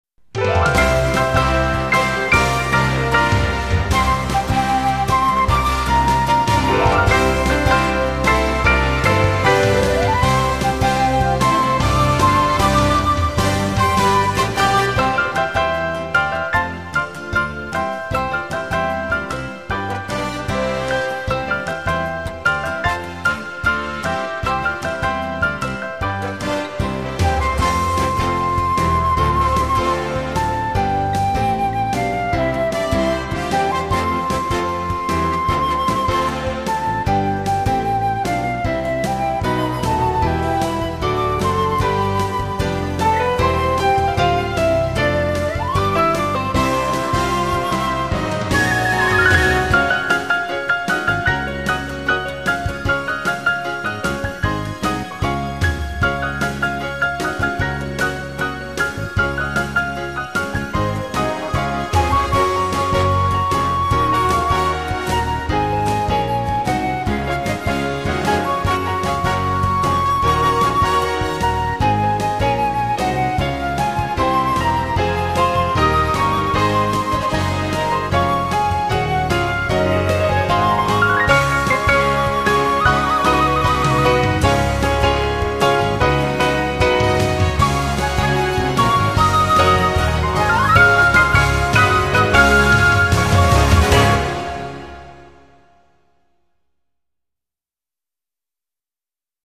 How Majestic Is Your Name band, v.a. 10 sec.
Kw_How_Majestic_Is_Your_Name_Instrumental1.mp3